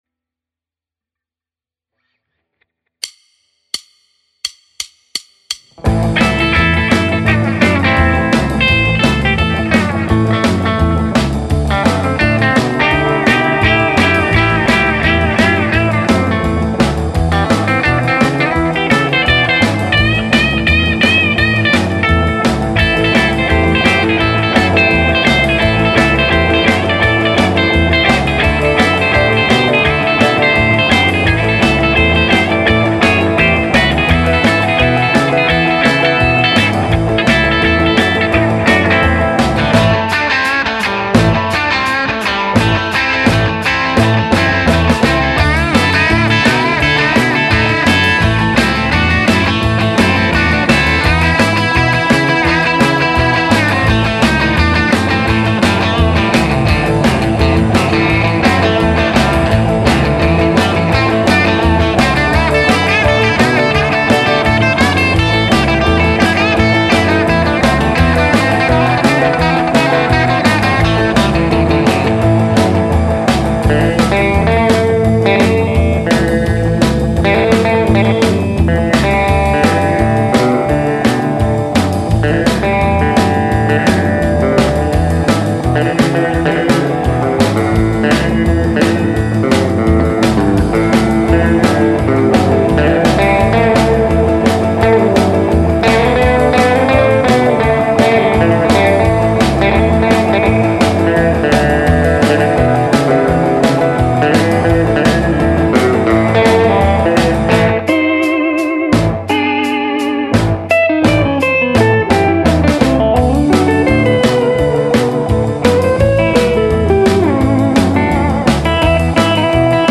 Jam... rock and roll?
Jamble jam rnr 2.mp3 60's Rock & Roll Guitar Backing Track in A